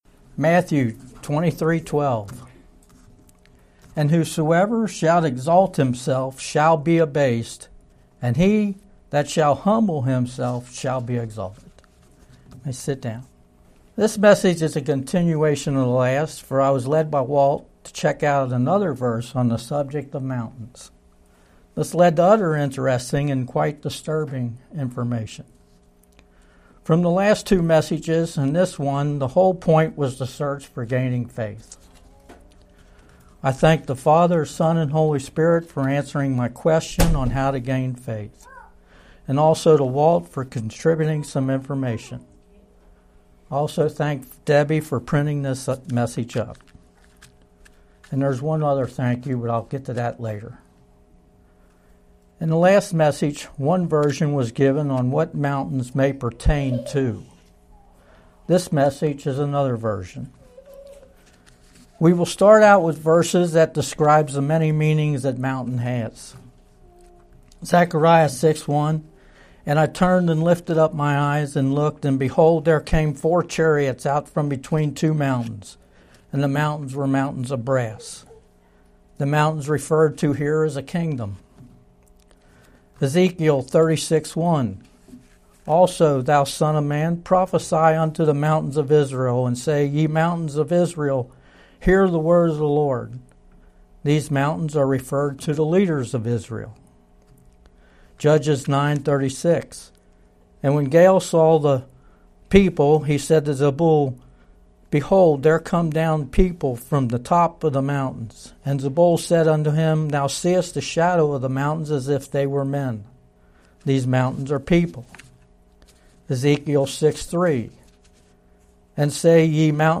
2021 Sermons